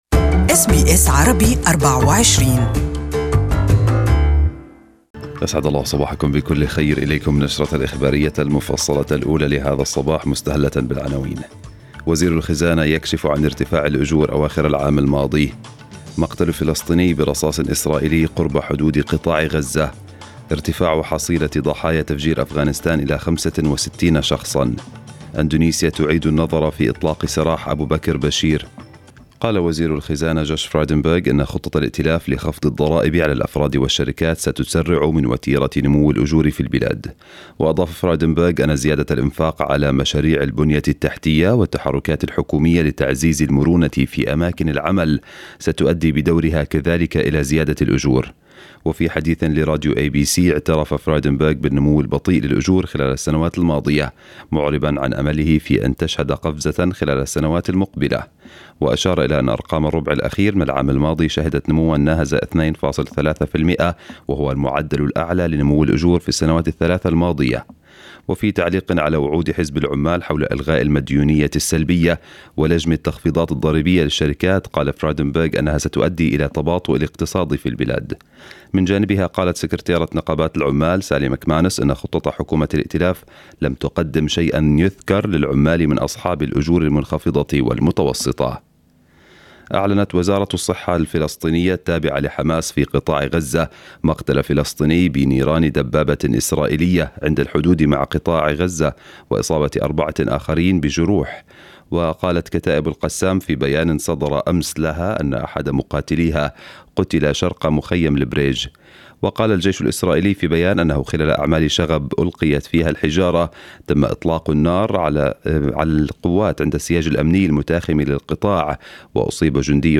News bulletin in Arabic for the morning